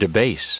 1147_debase.ogg